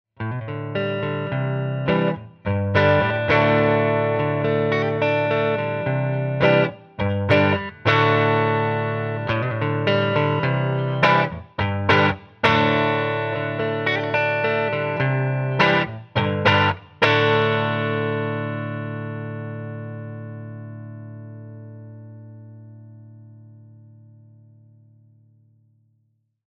50R (Broadcaster type rhythm pickup) alone